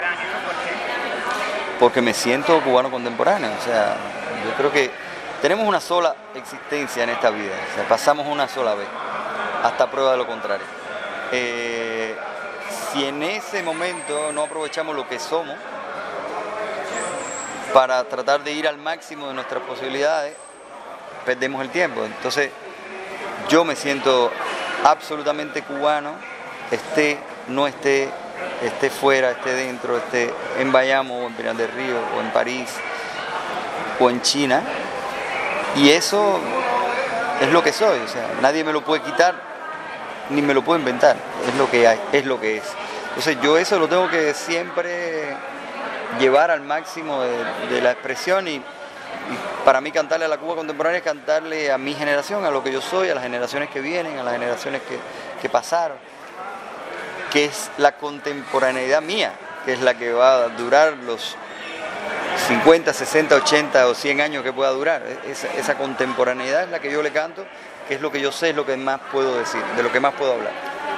Coloquial, risueño y con su habitual desenfado al decir, recibió el prolífico autor e intérprete a La Demajagua, aun sin previa coordinación de esta entrevista y como decimos en buen cubano, con el estómago vacío.
Declaraciones-de-Ra--l-Paz.mp3